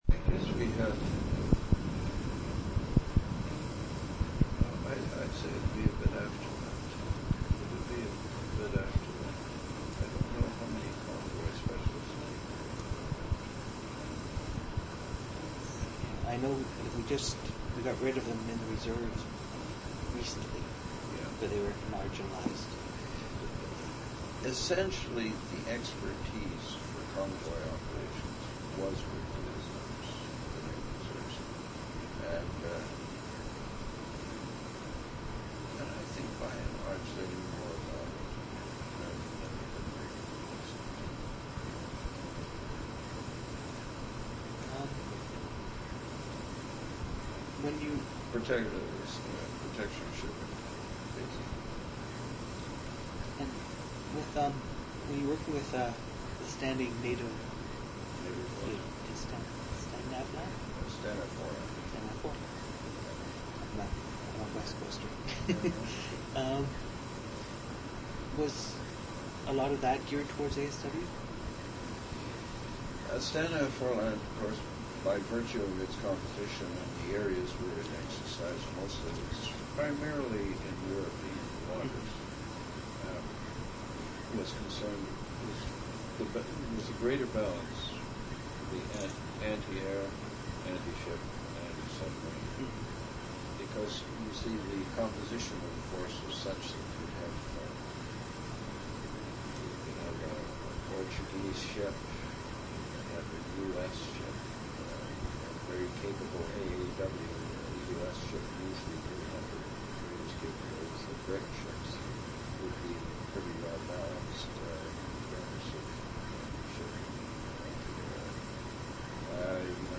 An interview/narrative